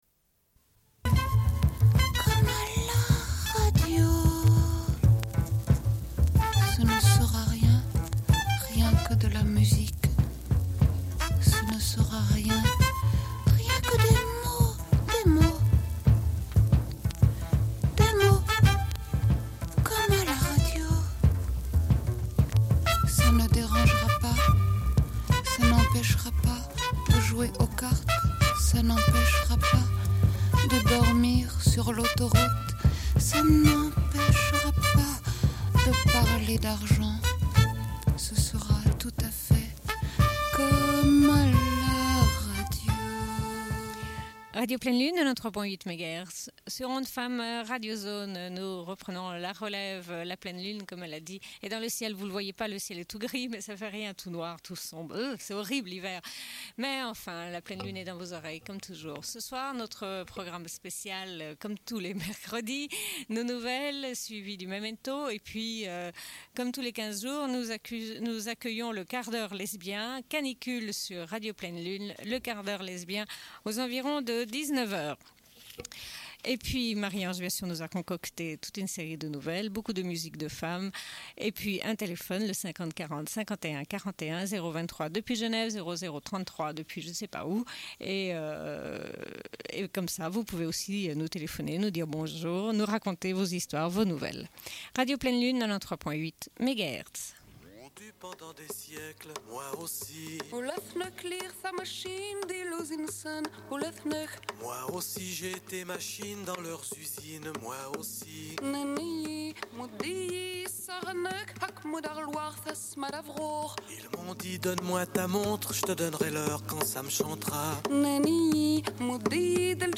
Une cassette audio, face B00:29:01